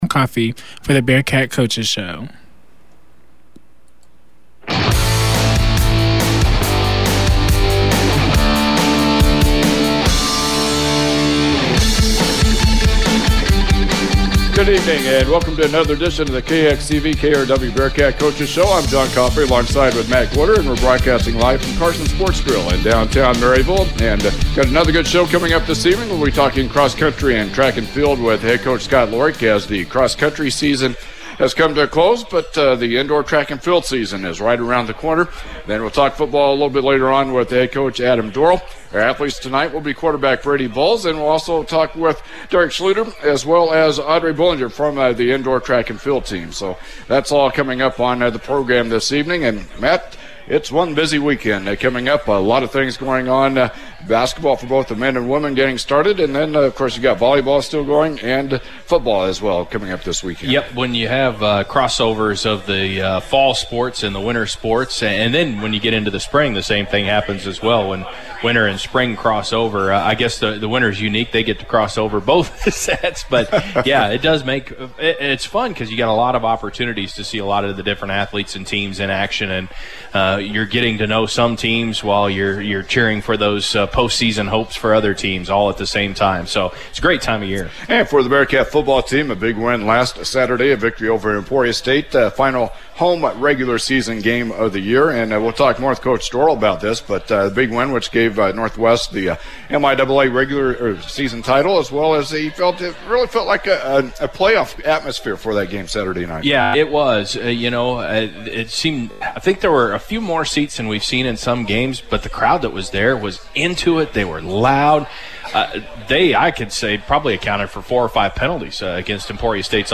Local Sports